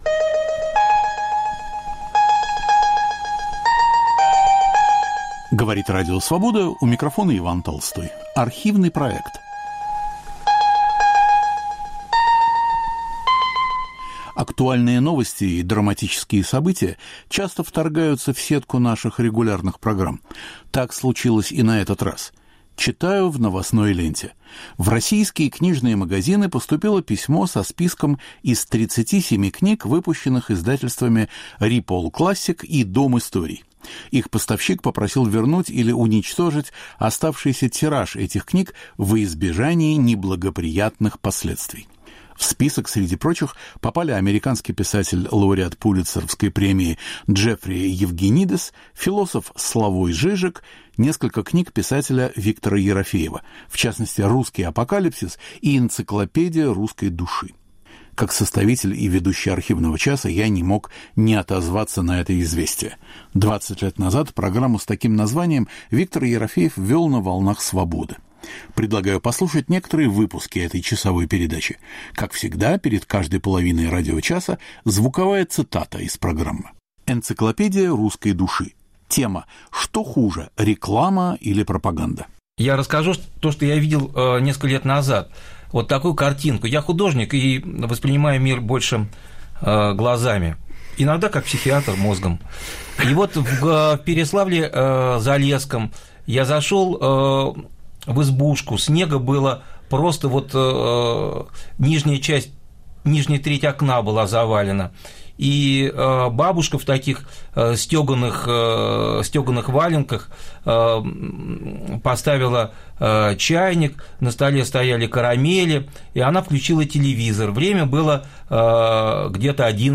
Передача Виктора Ерофеева. В студии Дмитрий Дибров и Андрей Бильжо.